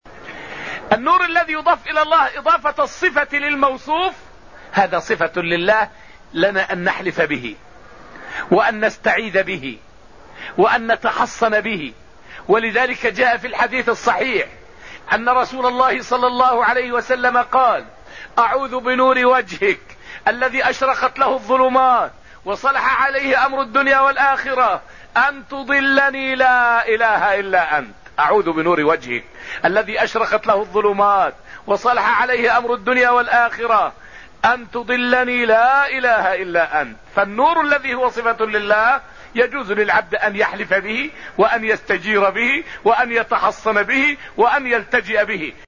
فائدة من الدرس السادس من دروس تفسير سورة النجم والتي ألقيت في المسجد النبوي الشريف حول جواز الحلف بنور الله تعالى ودليله من السنة المطهرة.